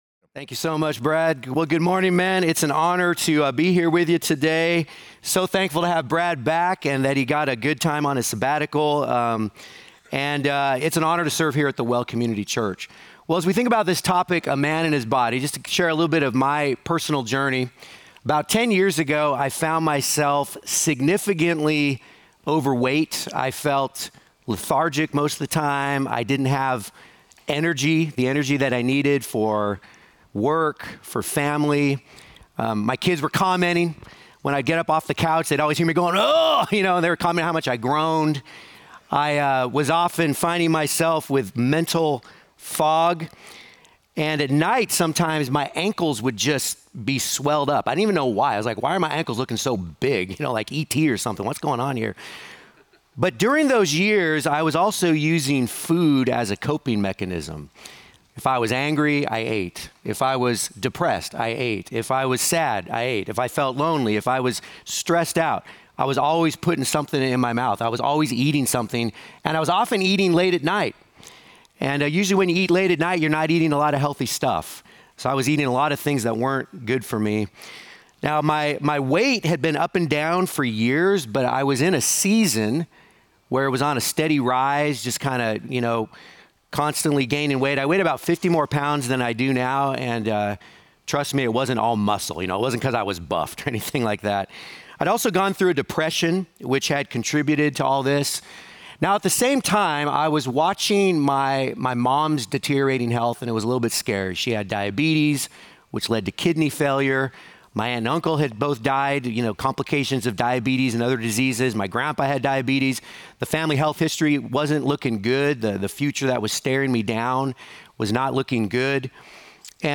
A Man and His Body (Audio & Photos Only) | Men's Breakfast
Message Audio